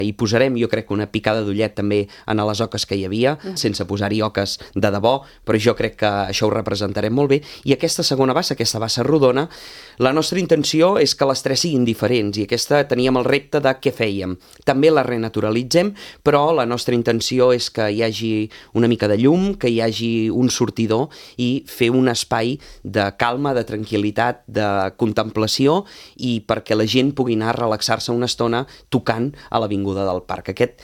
El tinent d’Alcaldia d’Activitat Cultural, Josep Grima, ha explicat a l’entrevista política de RCT que el repte és seguir millorant, però que no es planteja modificar el format de tres dies malgrat les cues per entregar les cartes als Patges Reials o participar en alguns tallers.